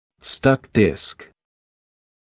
[stʌk dɪsk][стак диск]прилипший диск; застрявший диск (также stuck disc)